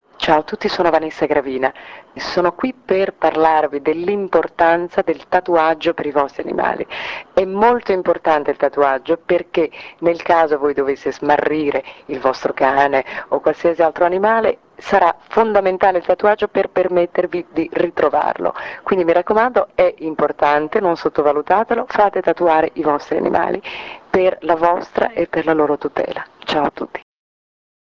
ASCOLTA GLI SPOT DI VANESSA GRAVINA
Spot 3 (Invito a tatuare i cani...)